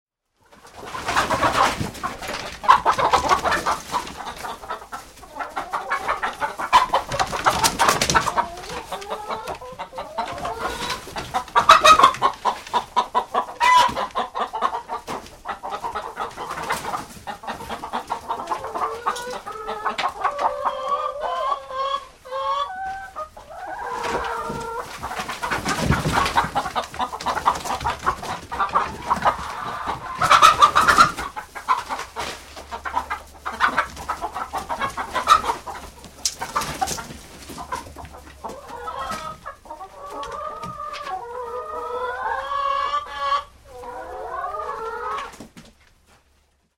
Шум переполоха в курятнике